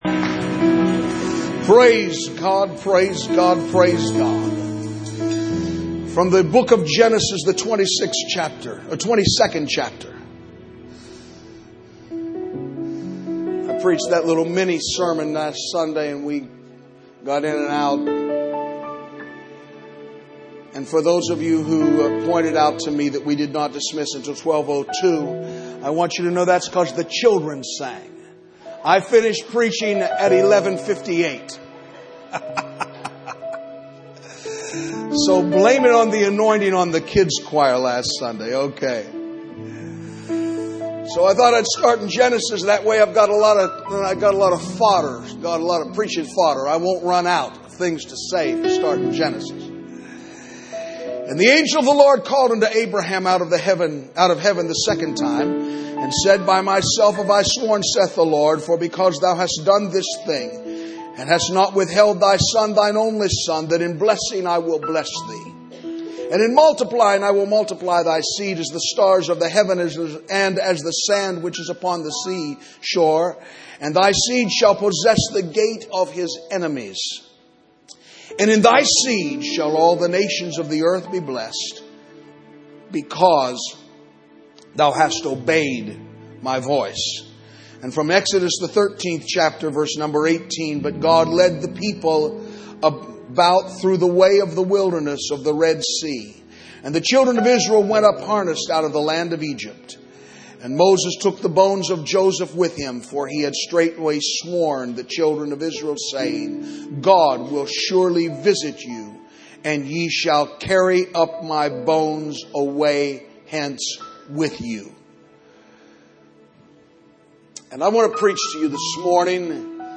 The Calvary Apostolic Church Sermon Archive